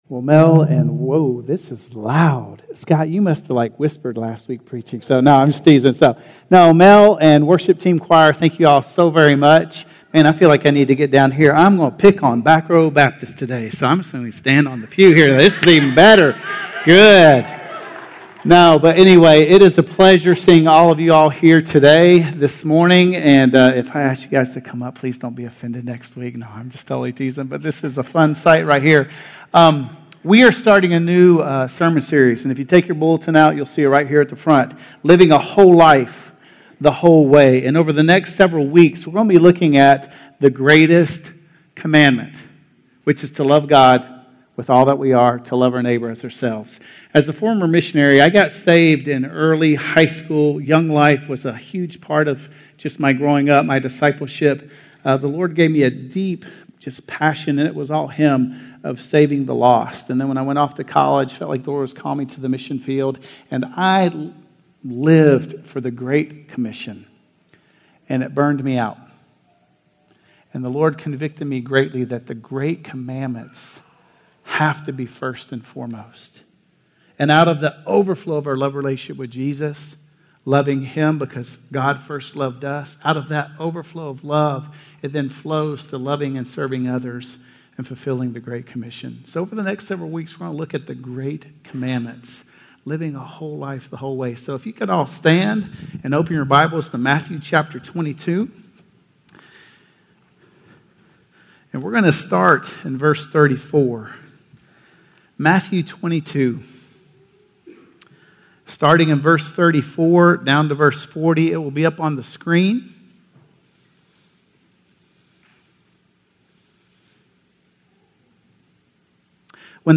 Loving God - Sermon - Woodbine